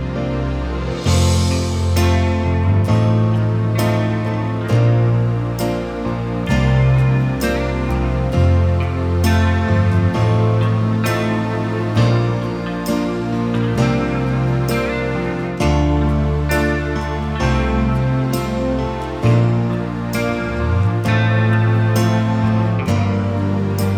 no Backing Vocals Jazz / Swing 4:04 Buy £1.50